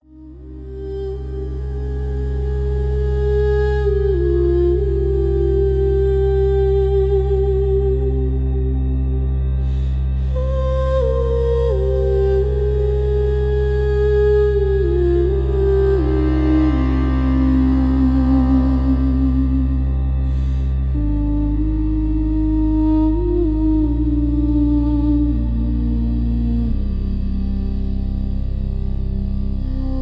Instrumental, background music